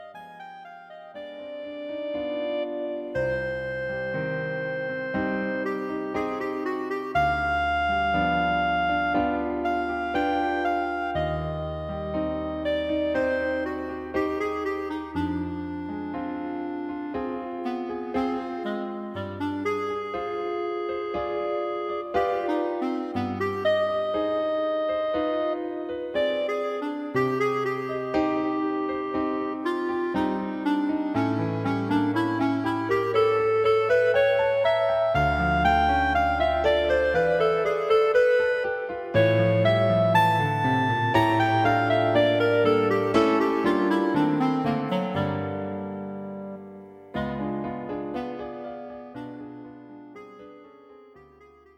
Clarinet and Piano
The audio clip is the second movement - Lento.